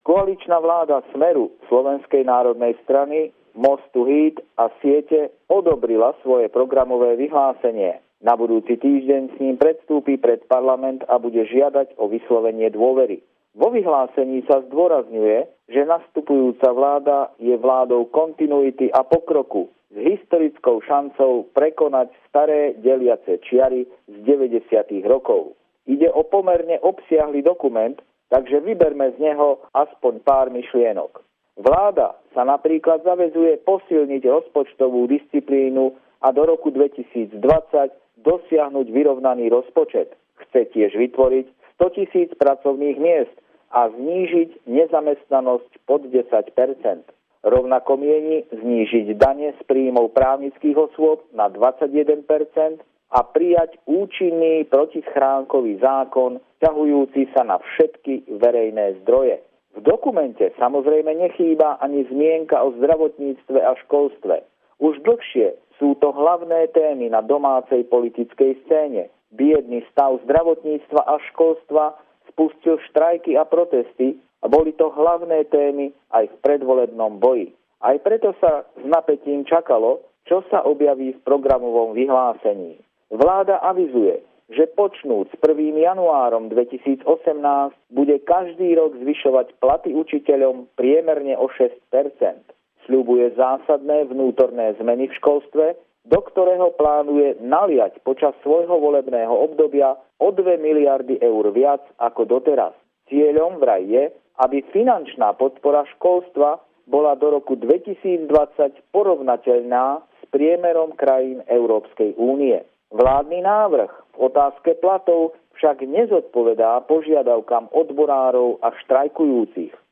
Pravidelný telefonát týždňa z Bratislavy